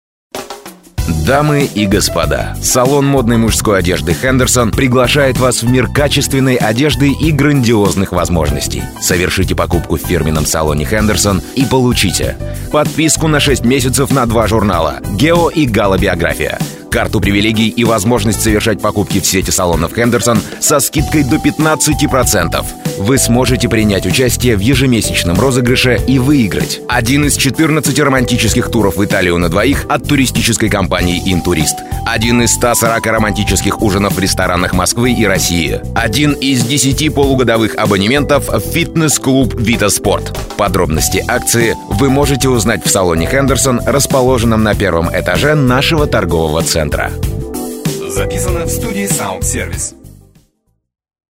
Информационный аудиоролик может быть музыкально оформлен, такой аудиоролик называется информационно-музыкальный аудиоролик. Голосовое озвучивание рекламного текста осуществляется одним или двумя дикторами (М или Ж) под индивидуально подобранную оригинальную музыку, используемую в качестве фона. Возможно присутствие звуковых эффектов, семплов - содержит конкретное рекламное предложение о товаре, услуге, бренде, акции.